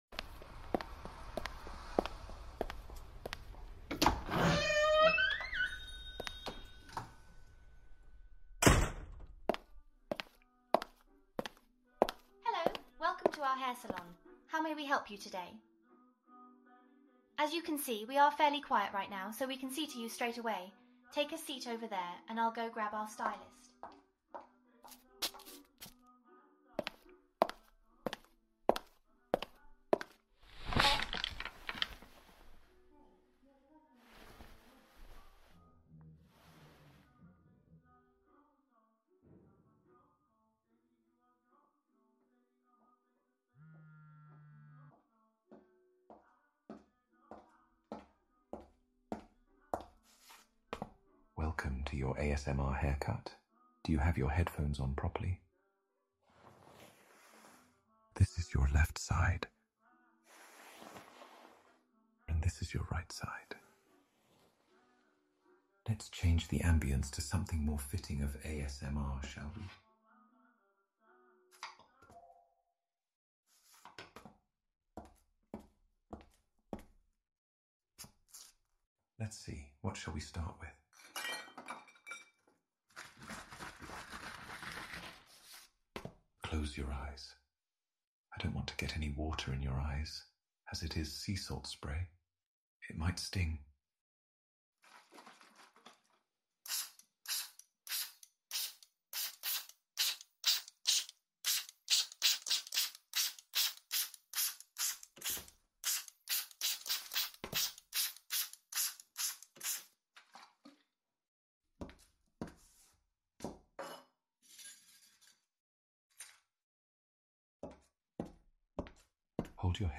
The most realistic 8D barber sound effects free download
The most realistic 8D barber hair cut on the internet. It will trick your ears with the 3d audio